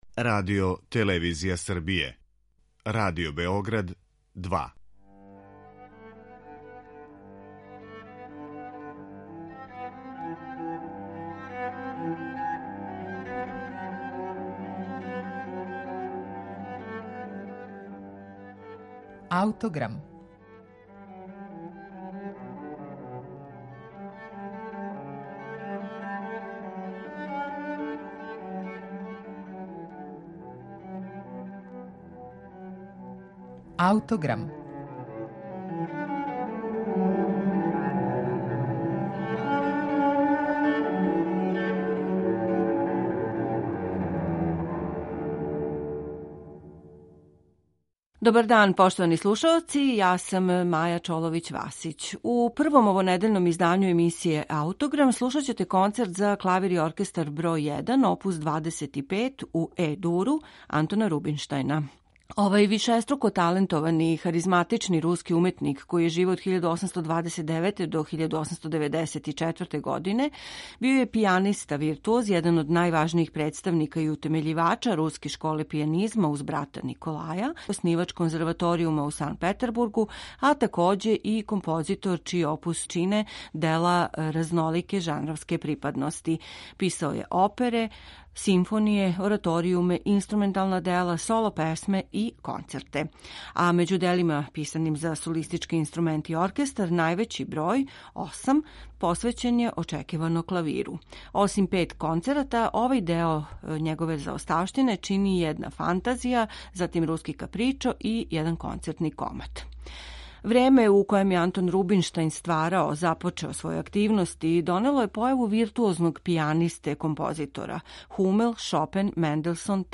Међу делима писаним за солистички инструмент најраније компонован је Концерт за клавир оп. 25 у Е-дуру.